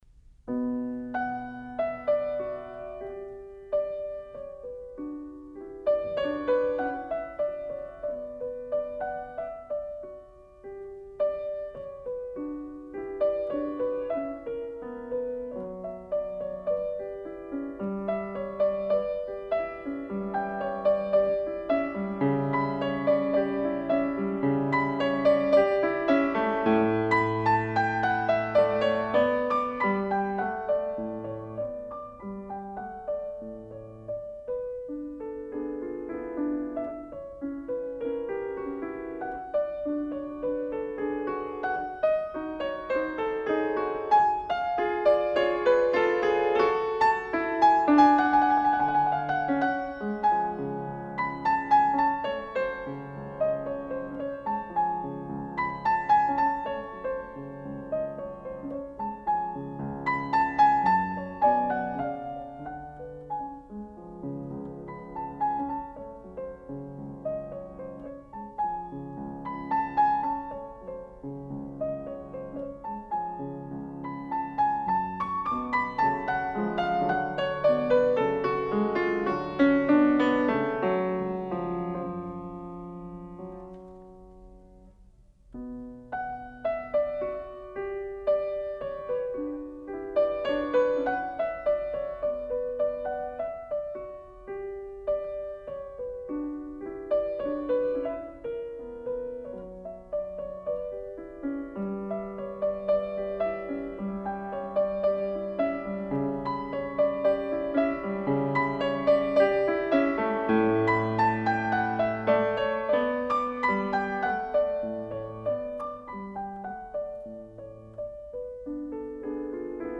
Сонаты для фортепиано.